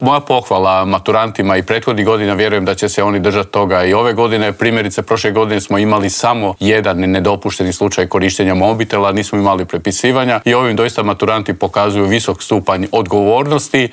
ZAGREB - U Intervjuu tjedna Media servisa razgovarali smo s ravnateljem Nacionalnog centra za vanjsko vrednovanje obrazovanja, Vinkom Filipovićem.